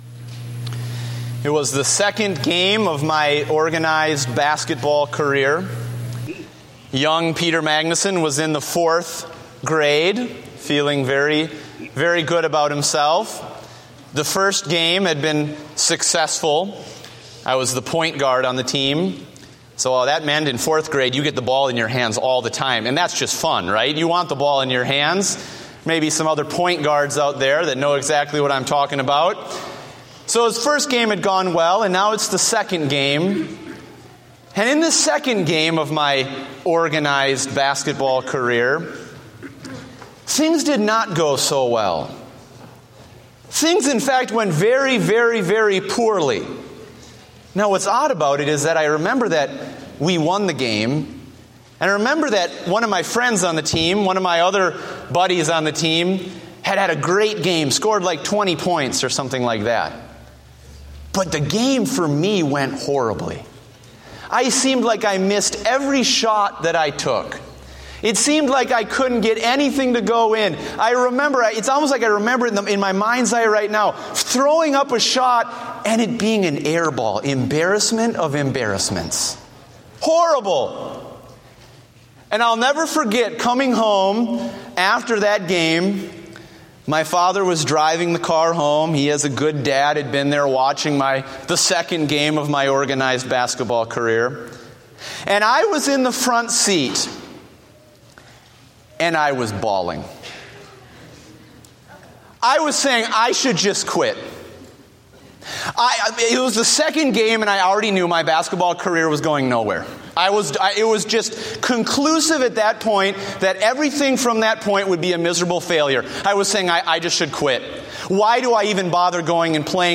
Date: April 26, 2015 (Morning Service)